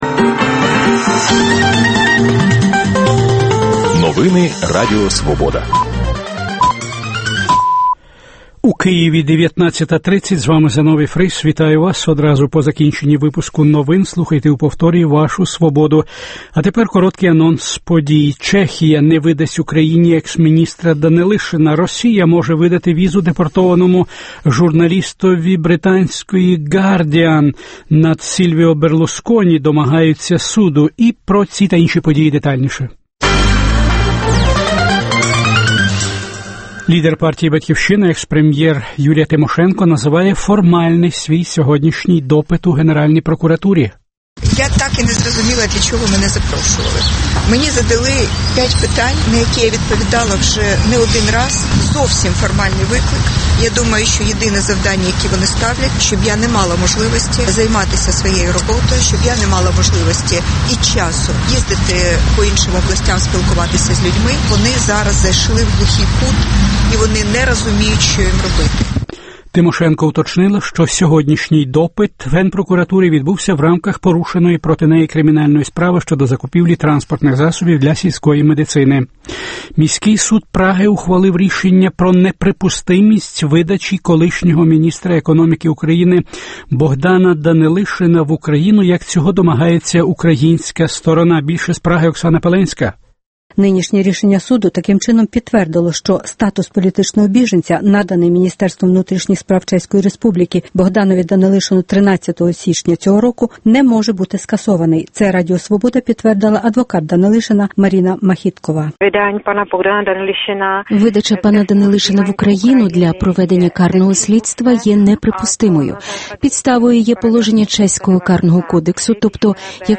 Дискусія про головну подію дня. (Повтор денного випуску)